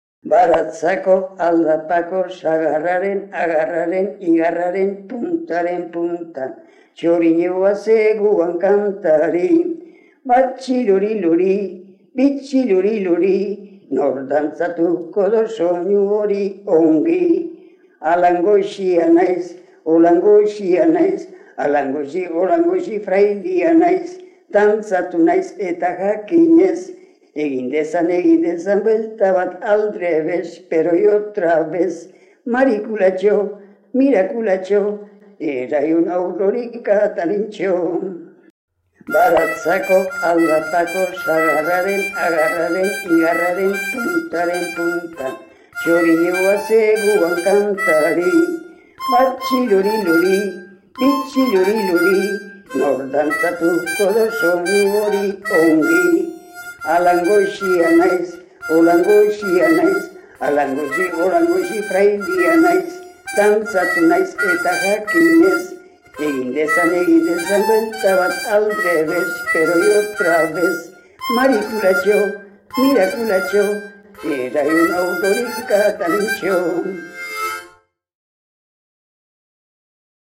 play pause stop mute unmute previous next Almute dantza de Iribas. Cantada.